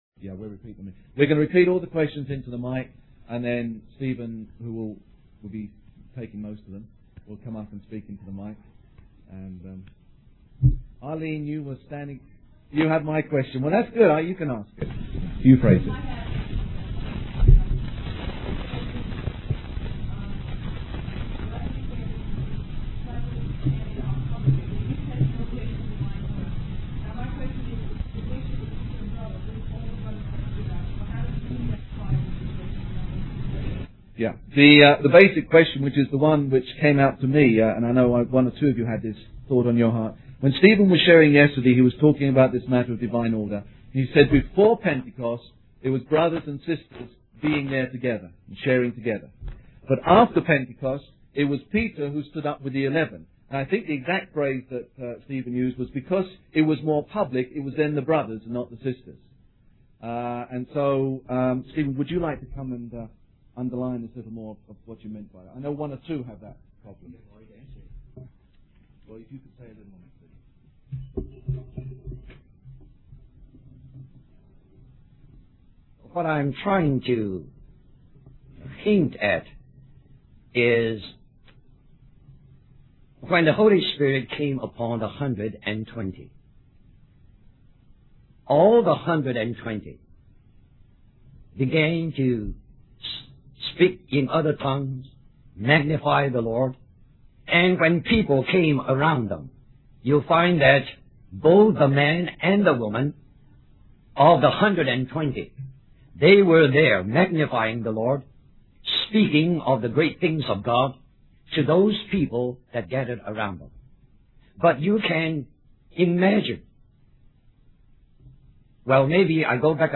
Question and Answer